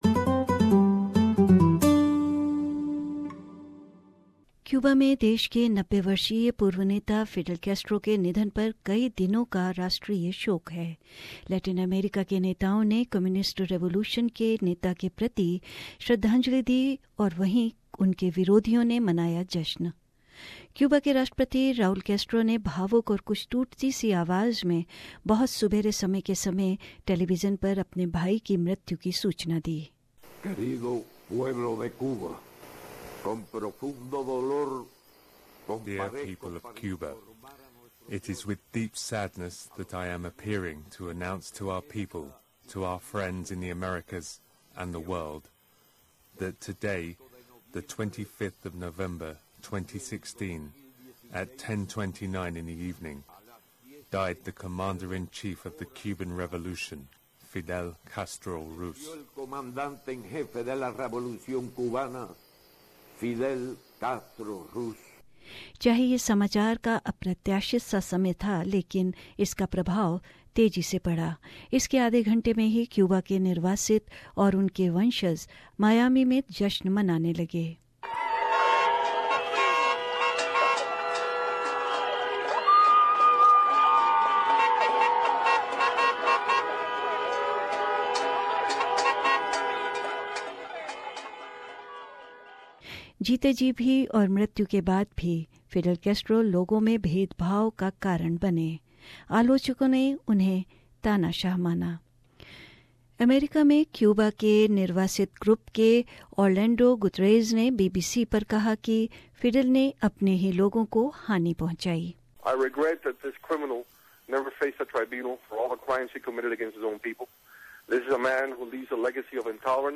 एक फीचर सुनिये ...